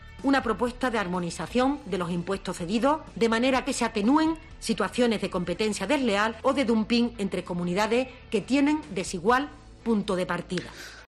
Palabras de María Jesús Montero